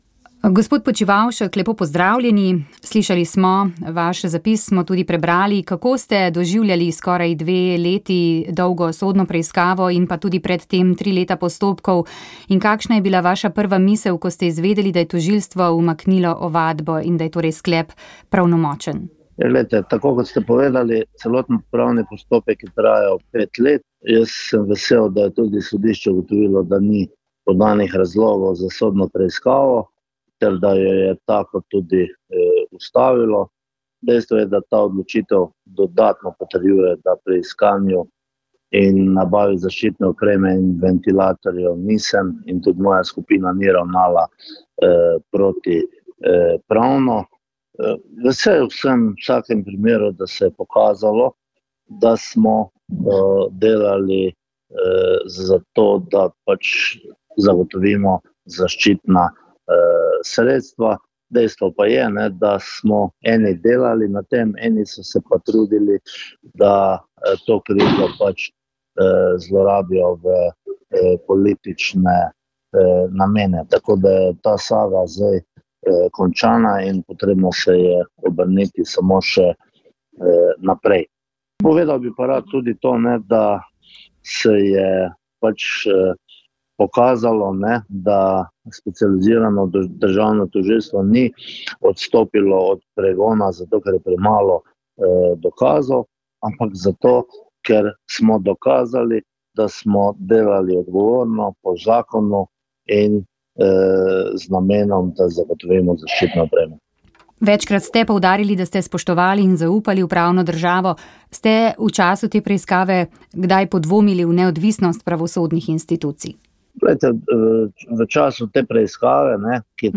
Sporočilo ob božični smreki na trgu pred baziliko sv. Petra v Rimu - minister za zunanje zadeve Anže Logar